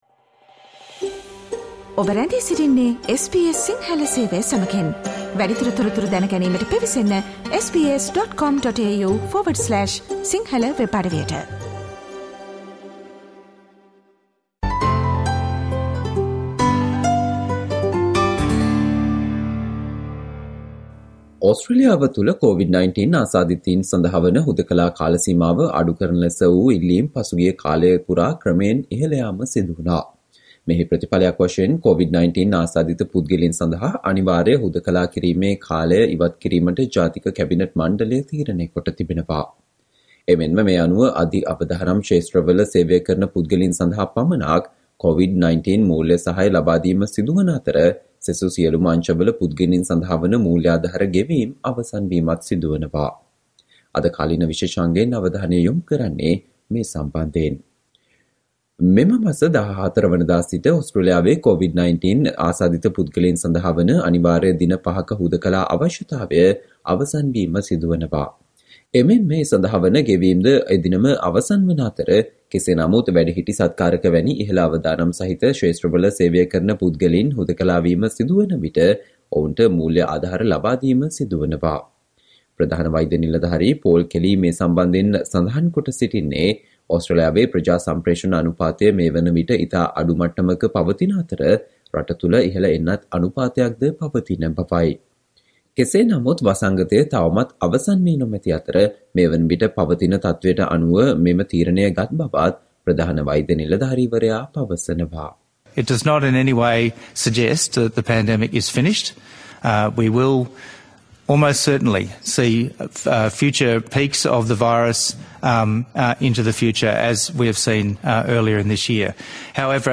Today - 03 October, SBS Sinhala Radio current Affair Feature on Compulsory isolation for coronavirus to end on October 14th